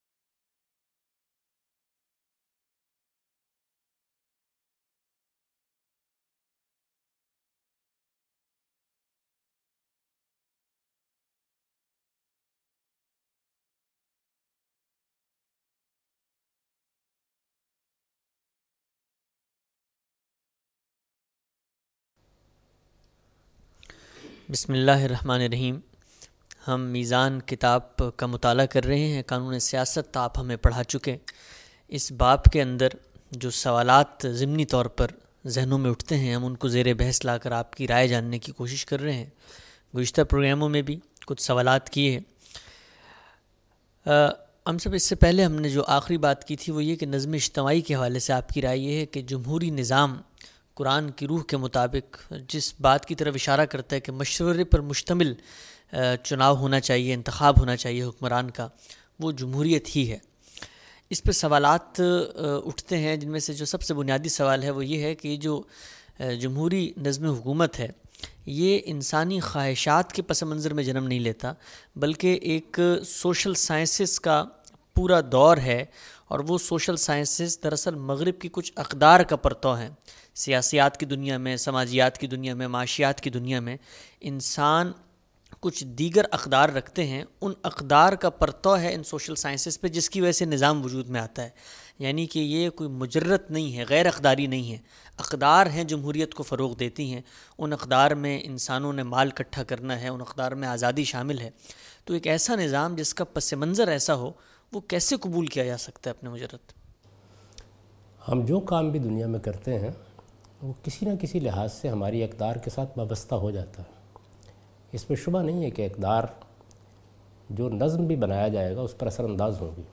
In this lecture he teaches the topic 'The Political Shari'ah' from 2nd part of his book. This sitting is a question answer session in which Ustazz Javed Ahmed Ghamidi answers important questions about his understanding of social shari'ah.